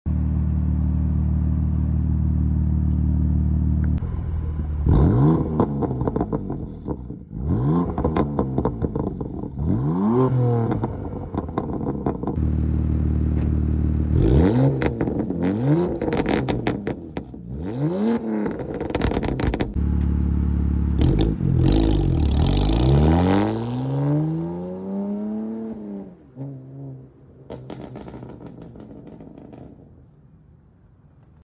Zero rasp s55 exhaust set sound effects free download
Arm Resonated downpipe, AA Equal Length Resonated midpipe, stock m4 comp rear section!